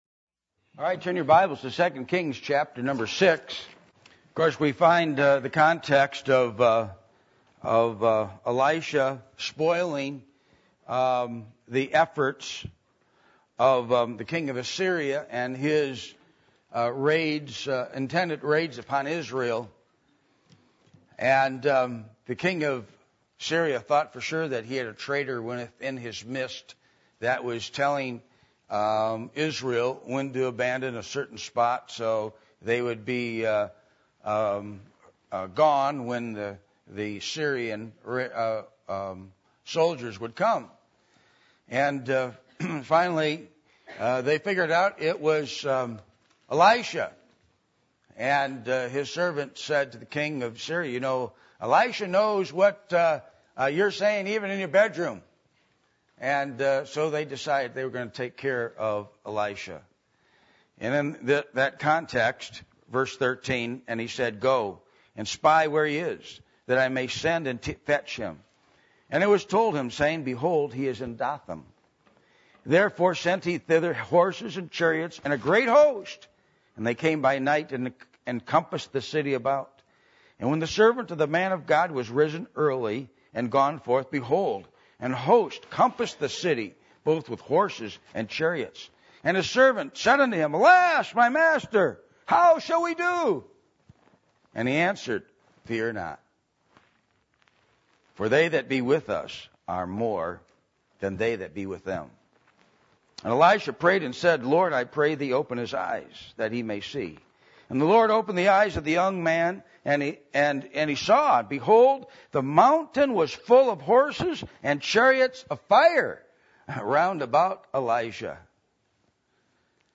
2 Kings 6:13-17 Service Type: Sunday Evening %todo_render% « The Conclusion Of The Bible Communion With The Triune God